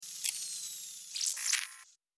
Звук выключения прибора ночного видения